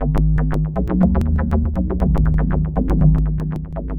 Rhythmic Dub Ab 120.wav